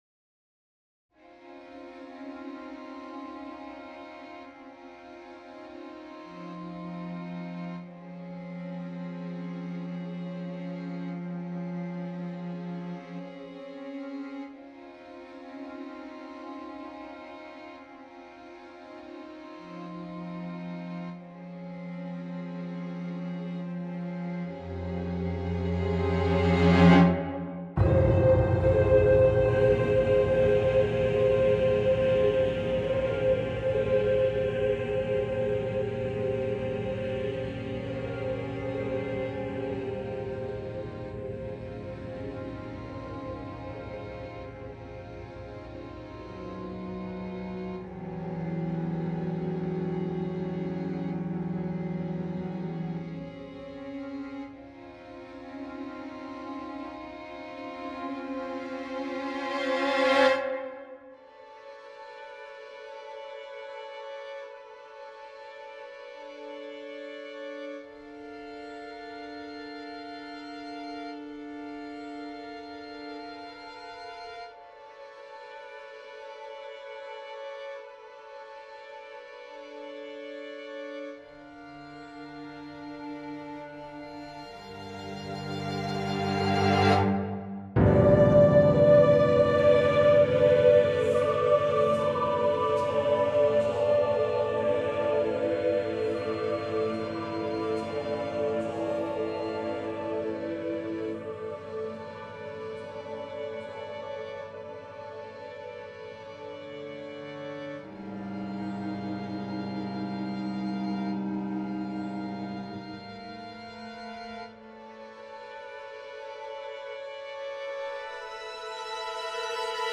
a choral and chamber music composition in nine movements